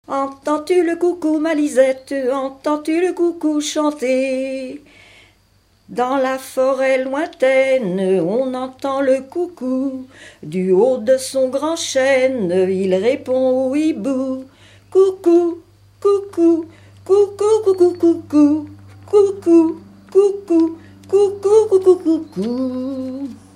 Localisation Saint-Mars-de-Coutais
Thème : 1080 - L'enfance - Enfantines diverses
Catégorie Pièce musicale inédite